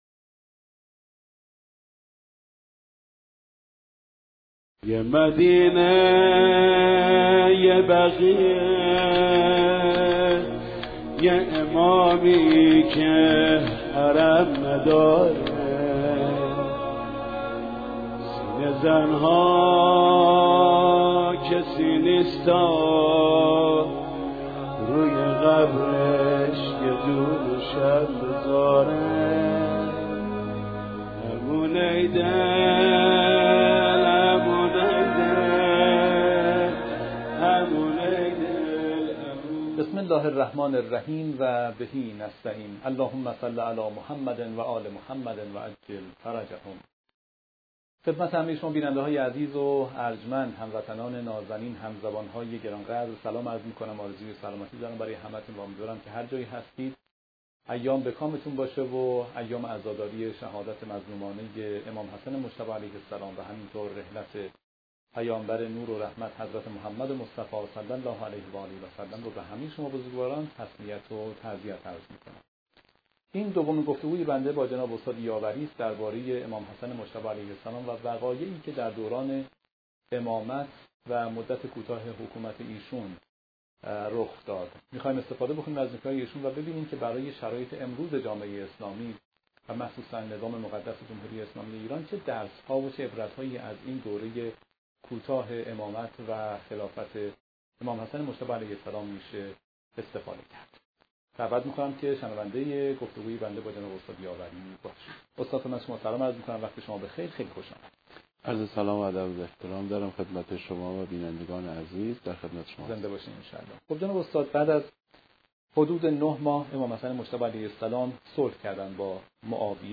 مجری برنامه با تسلیت ایام شهادت امام حسن مجتبی(ع) و رحلت پیامبر اکرم(ص)، هدف از گفتگو را بررسی درس‌ها و عبرت‌های دوران امامت امام حسن(ع) برای جامعه امروز اسلامی و نظام جمهوری اسلامی ایران بیان می‌کند. تحمیل بودن صلح و عدم انتخاب اول امام: صلح امام حسن(ع) یک اقدام تحمیلی بود و هرگز انتخاب اول ایشان نبود.